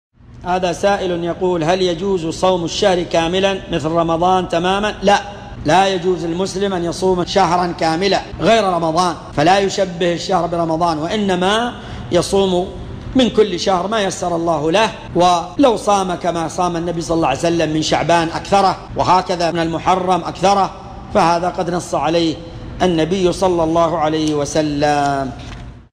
مقتطف من محاضرة بعنوان : (فضل الطاعة في شهر الله المحرم) .